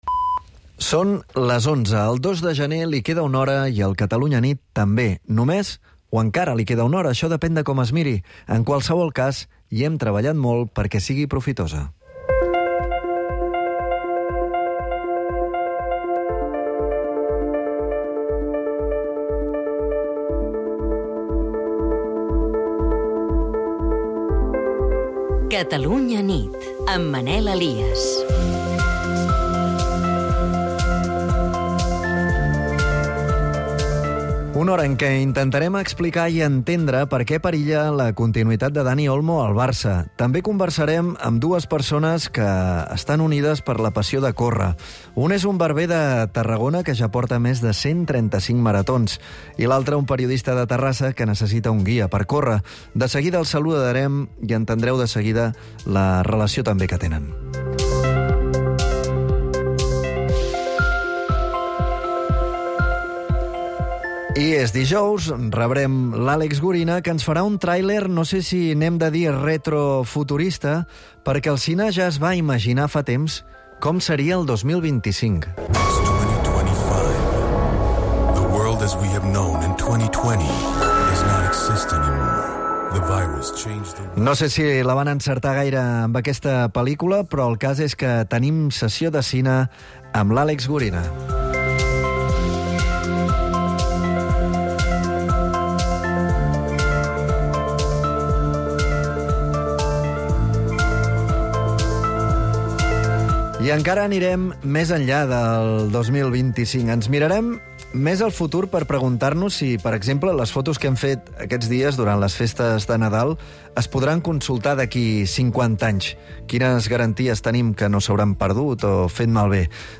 un informatiu diari, a les 22.00, per saber i entendre les principals notcies del dia, amb el plus d'anlisi amb el ritme pausat al qual convida la nit.